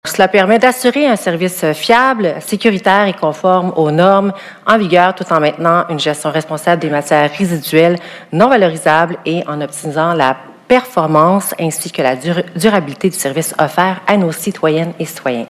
voici la conseillère Chrep Lok.